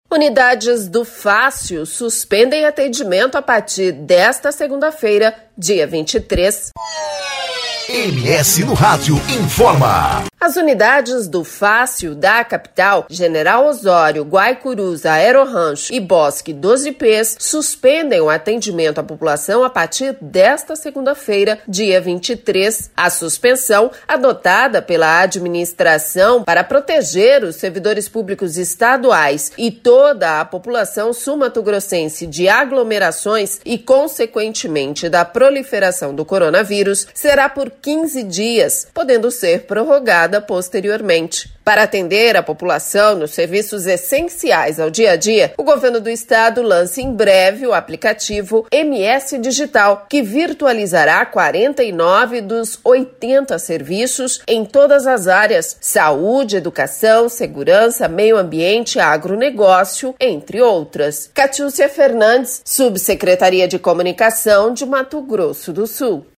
23.03-BOLETIM-FÁCIL-FECHA.mp3